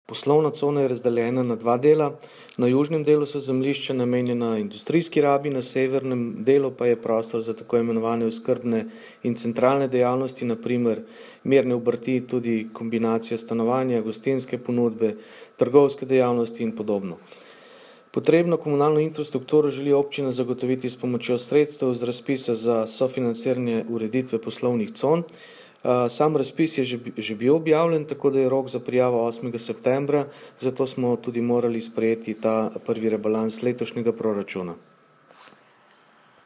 (-pripeta tonska izjava R. Goleta)
Tonska izjava_župan Rupert Gole (583kB)